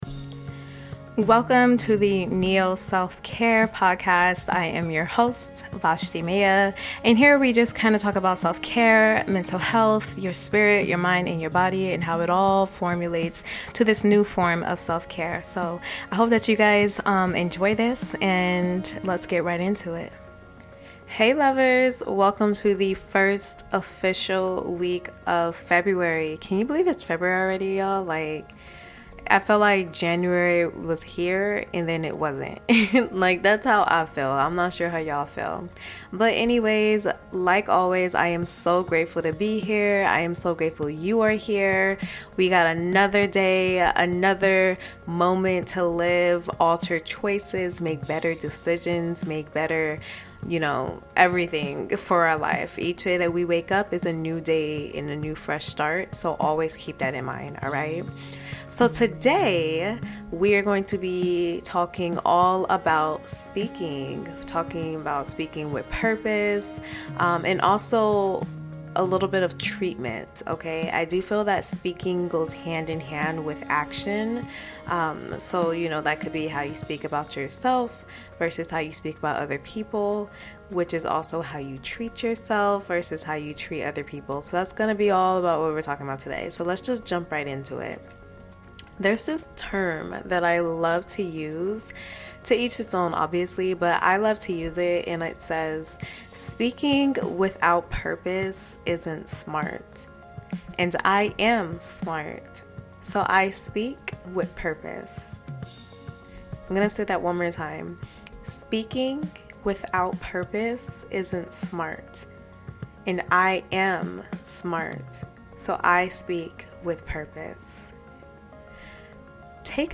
In this solo episode, I talk about the value of your inner dialect and the words you use.